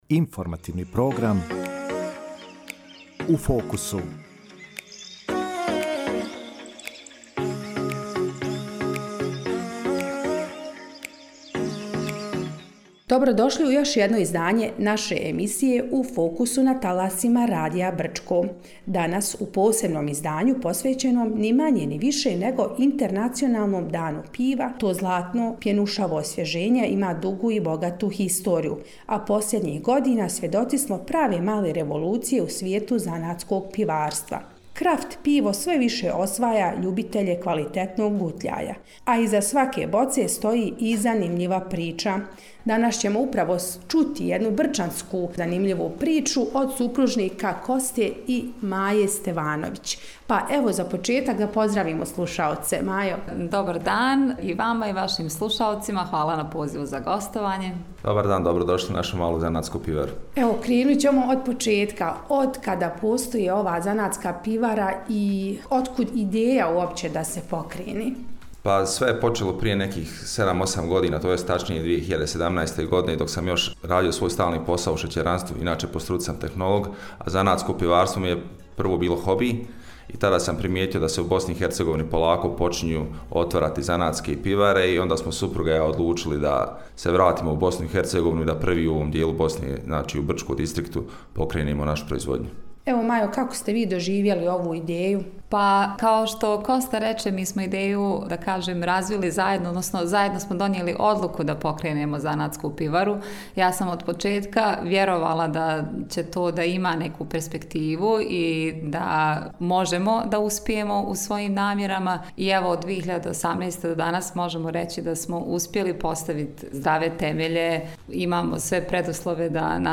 Sada već iskusni preduzetnici govorili su o počecima i preprekama koje stoje na putu prehrambene industrije manjih kapaciteta, ali koje su prevazišli iz prostog razloga – zbog velike ljubavi prema pjenušavom napitku. Zašto se kraft pivo razlikuje od onog iz masovne proizvodnje – poslušajte u emisiji.